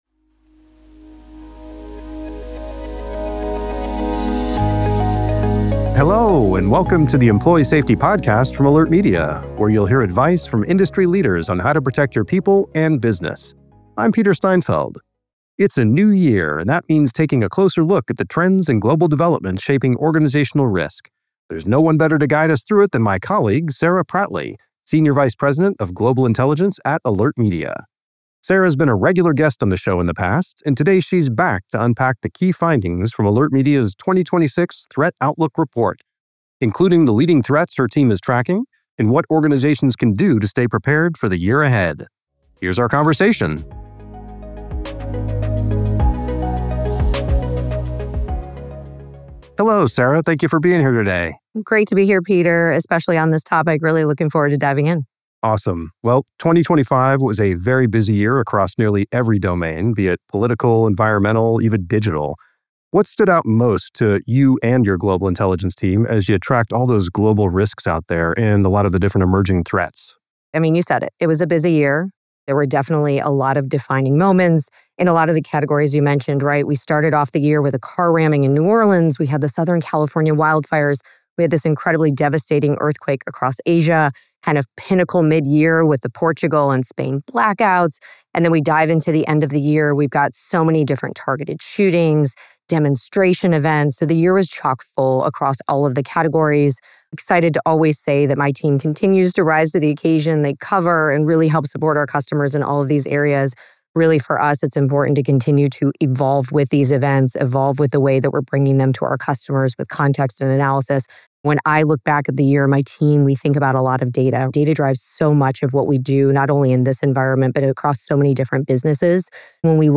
You can find this interview and many more by following The Employee Safety Podcast on Spotify or Apple Podcasts .